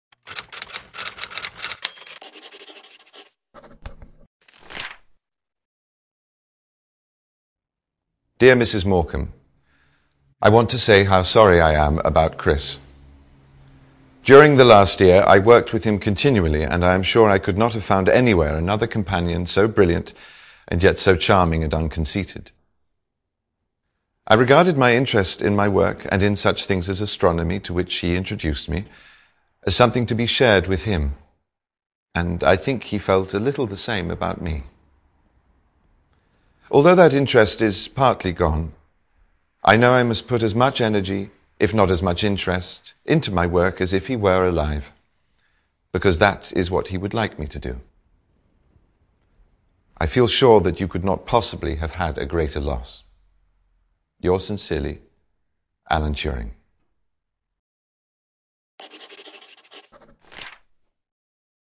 在线英语听力室见信如晤Letters Live 第18期:'卷福'读信:这是你人生中最悲痛的事的听力文件下载,《见信如唔 Letters Live》是英国一档书信朗读节目，旨在向向书信艺术致敬，邀请音乐、影视、文艺界的名人，如卷福、抖森等，现场朗读近一个世纪以来令人难忘的书信。